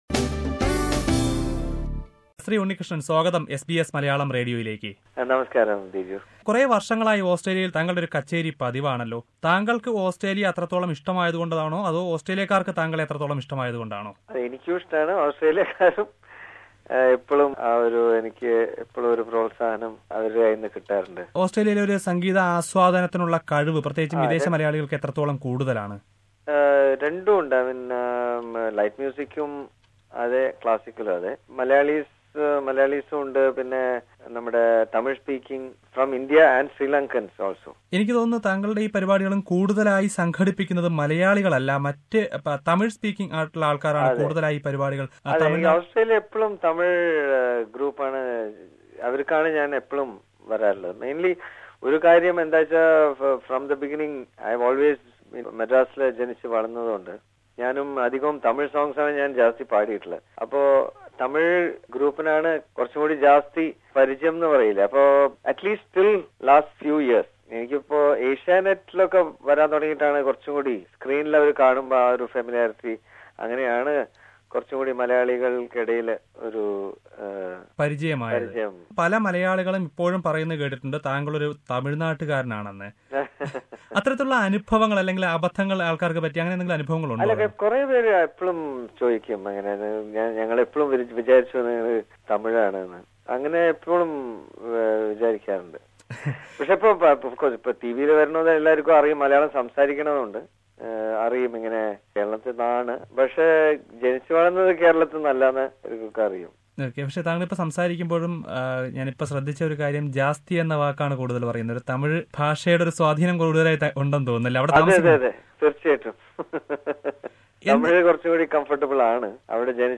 Unnikrishnan talked exclusively to SBS Malayalam during his recent visit to Australia. Listen to the first part of the interview.